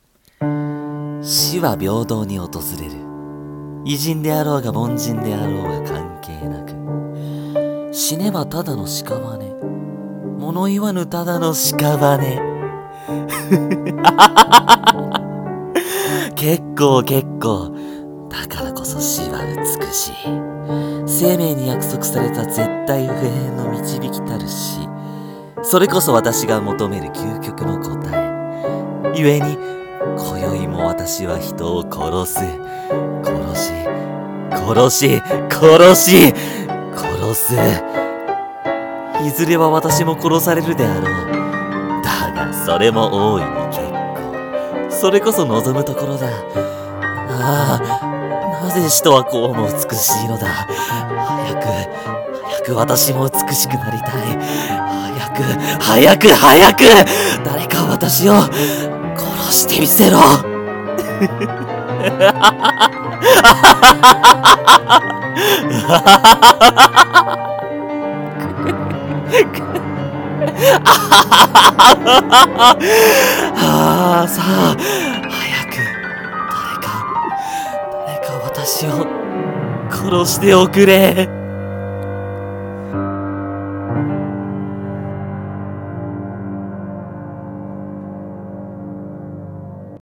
【声劇】題 : 殺人鬼